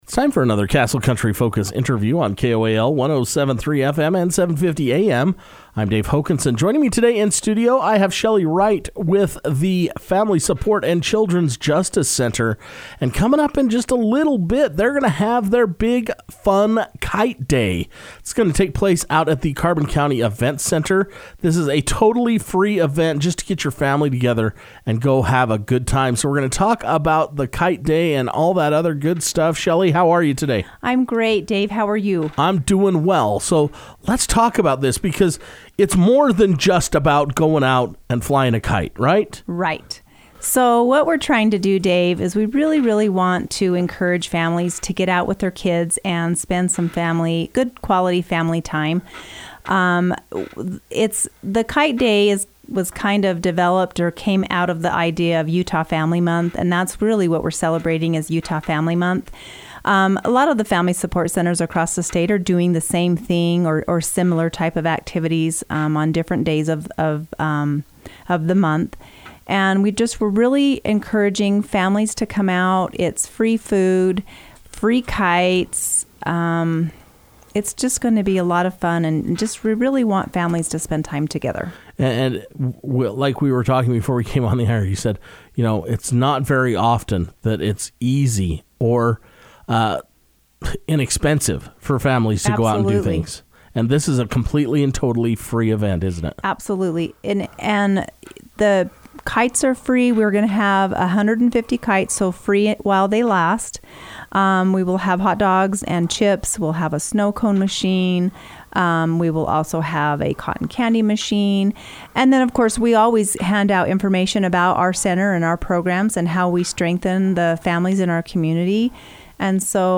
took time to come into the Castle Country Radio Station to share details about their upcoming event.